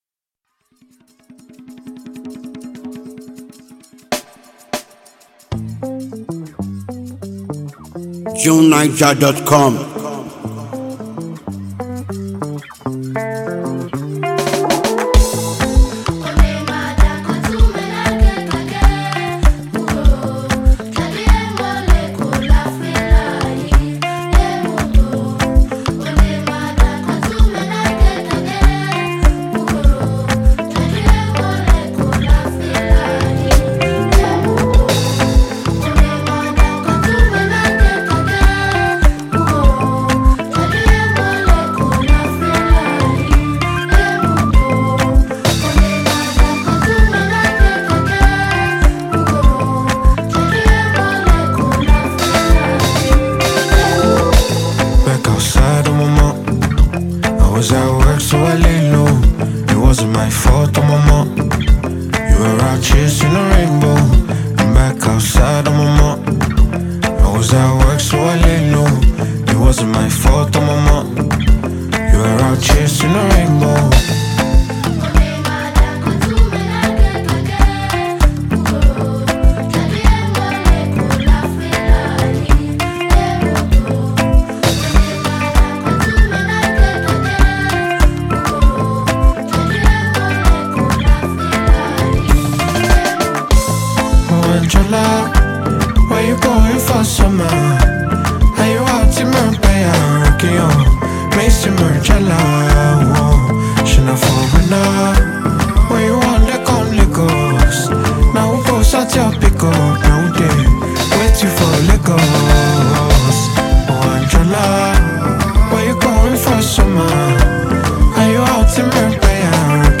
distinctive crisp percussion and evocative soundscapes
a relaxed yet rhythmic instrumental